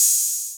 Open Hats
OpenHH SwaggedOut 4.wav